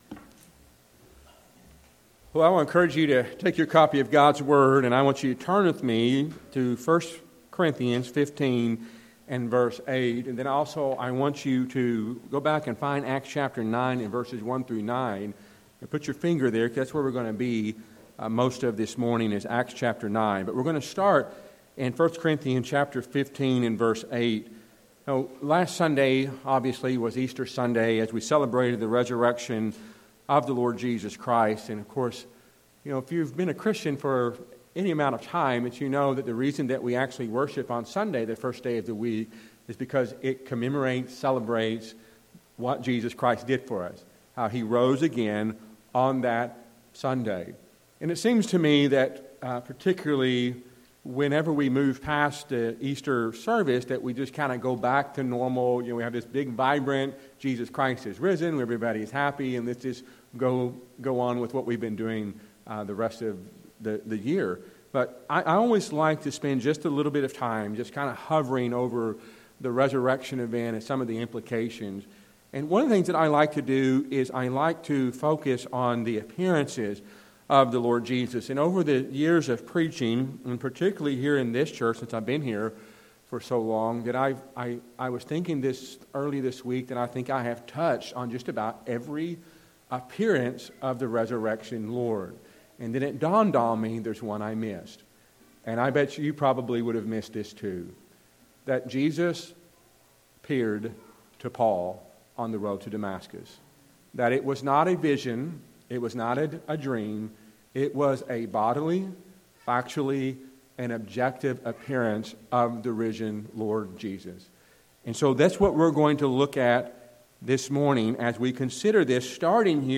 A sermon on the last resurrection appearance of Jesus from 1 Corinthians 15:8 and Acts 9:1-9.